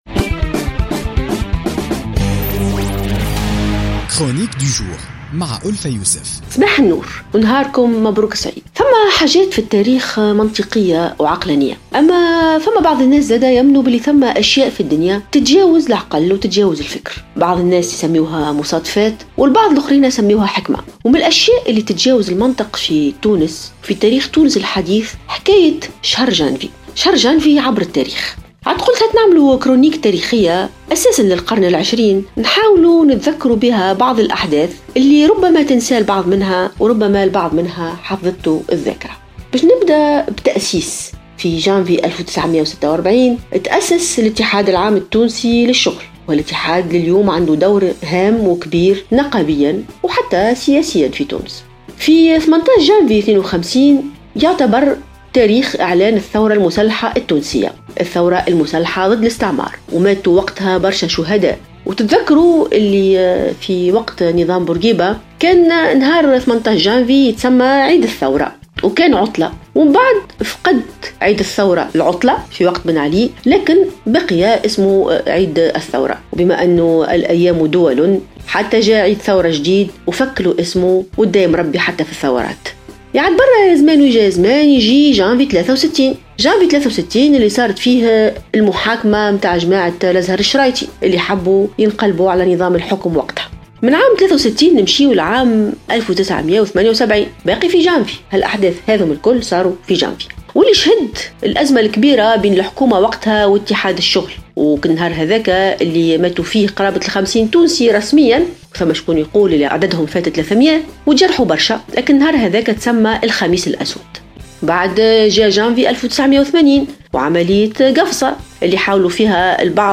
اعتبرت الكاتبة ألفة يوسف أن شهر جانفي "يتجاوز المنطق" تاريخيا في افتتاحيتها اليوم لـ "الجوهرة اف أم".